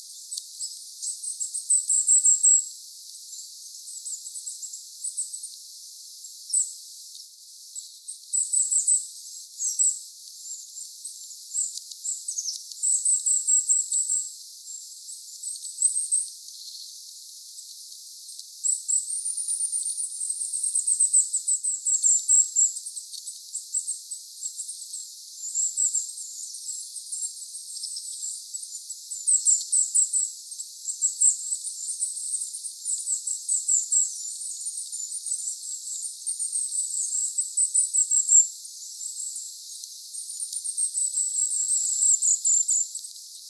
tässäkin aktiivinen hippiäinen
tassakin_aktiivinen_hippiainen.mp3